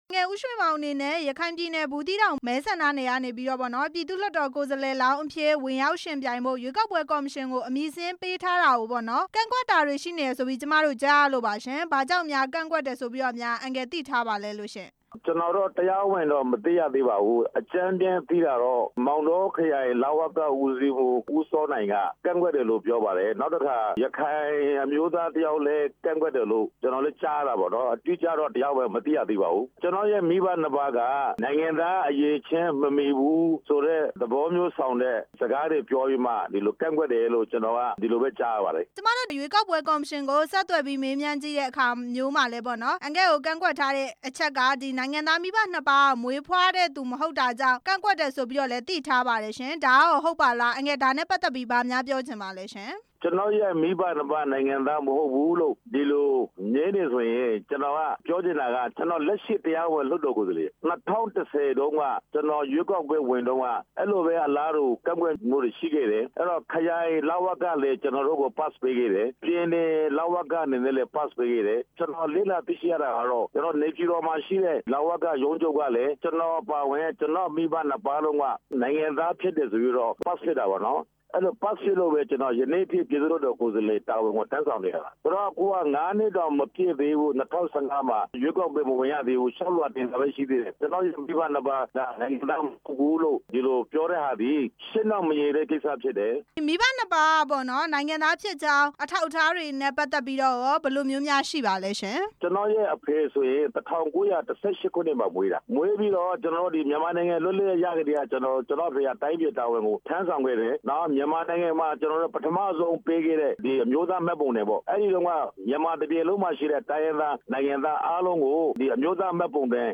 ပြည်သူ့လွှတ်တော် ကိုယ်စားလှယ် ဦးရွှေမောင် နဲ့ မေးမြန်းချက်